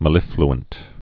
(mə-lĭfl-ənt)